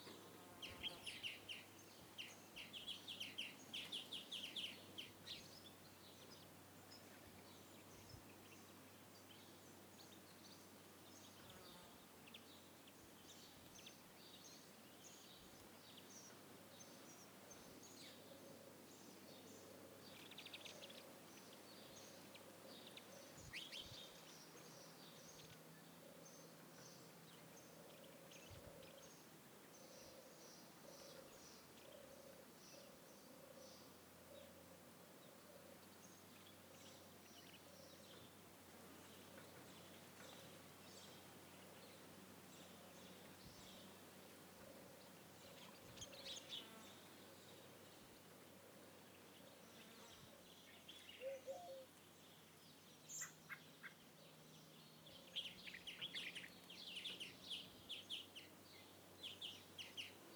MainForest-Calm-Birds.ogg